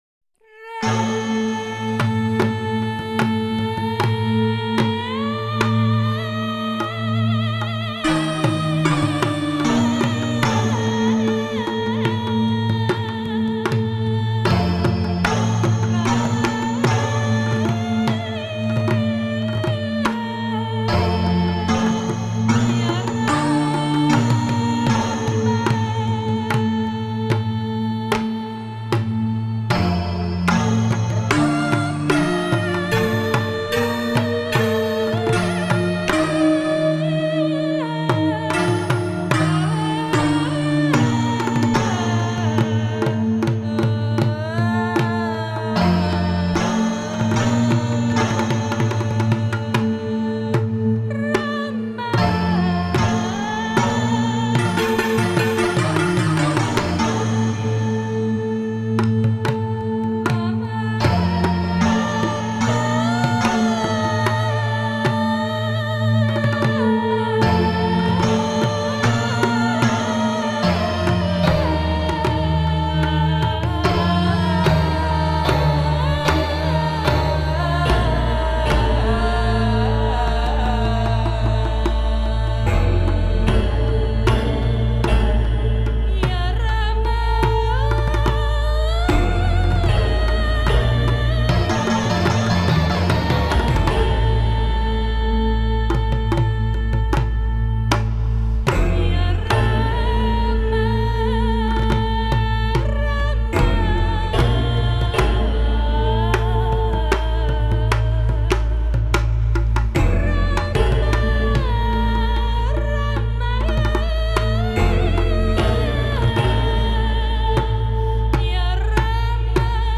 drums, Casio synthesizer, percussion, vibraphone, Synare II
bass, percussion, keyboards